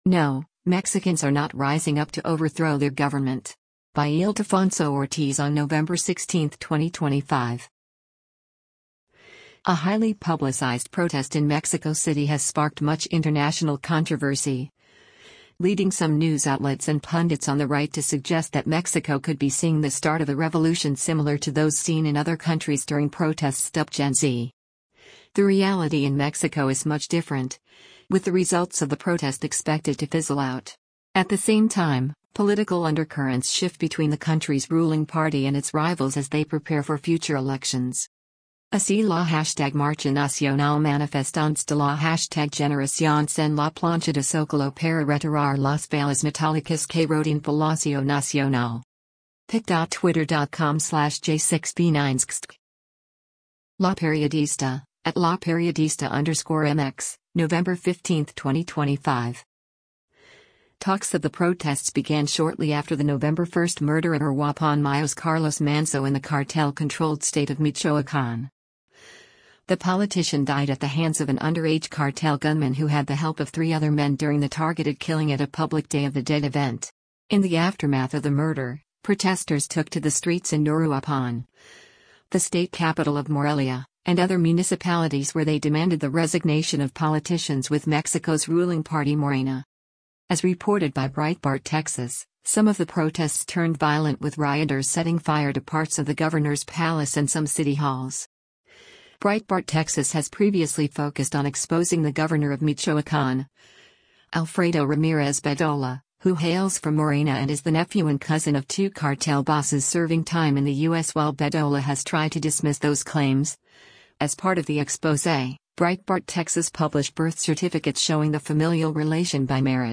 Surveillance video of a protest in Mexico City.